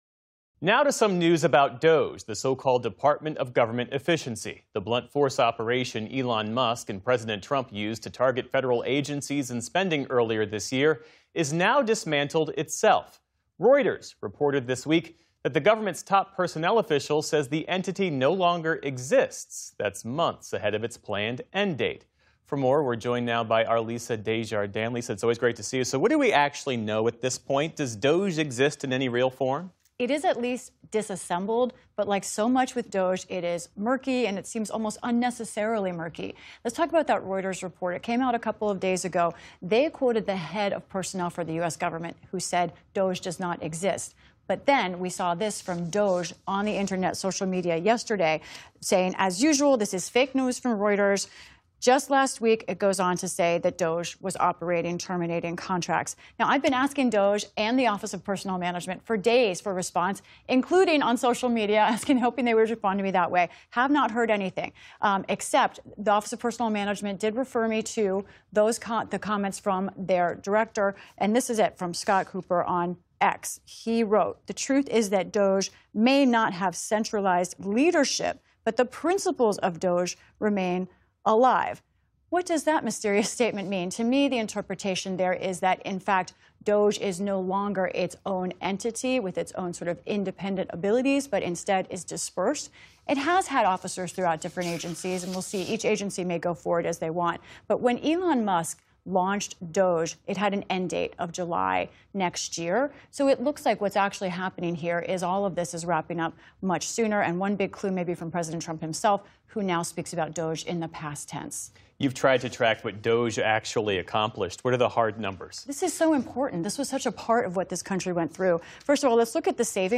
Lisa Desjardins reports.